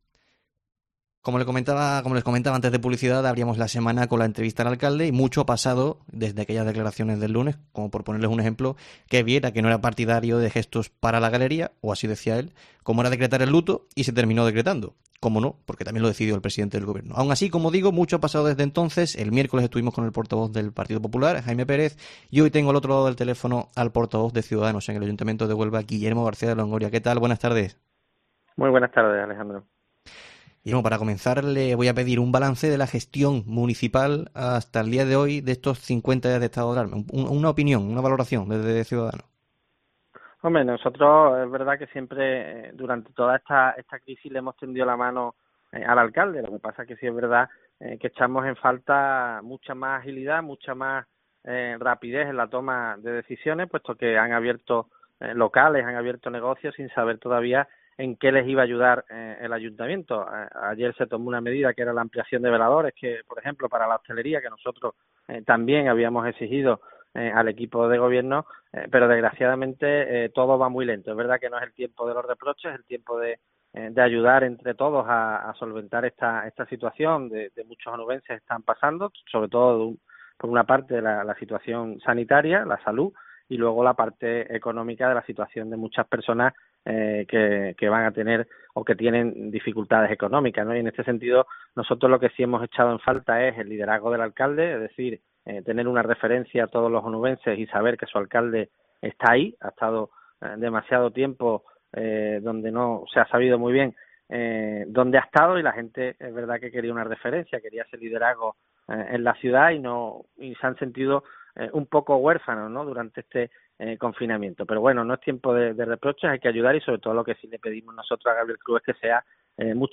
En el tiempo local de Herrera en COPE hemos hablado con el portavoz de la formación naranja que ha analizado la gestión del consistorio en esta crisis del Covid-19.